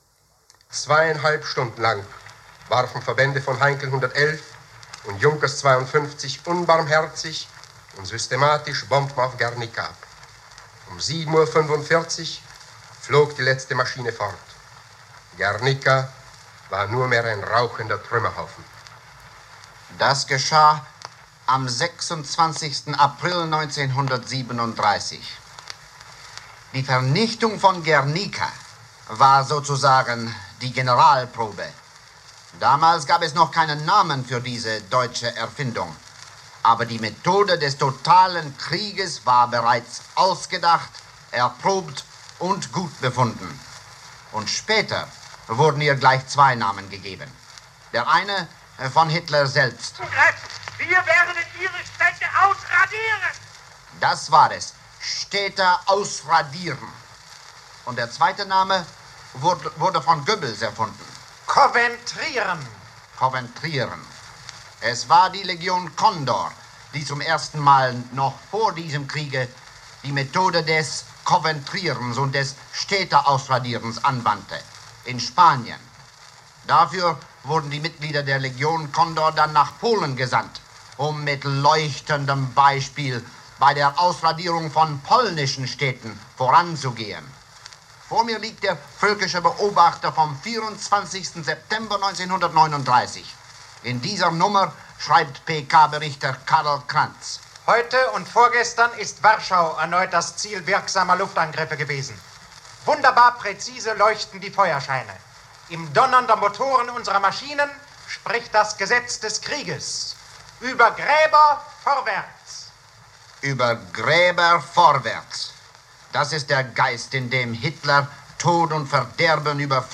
7. Sept. 1941, Kommentar zur Bombardierung deutscher Städte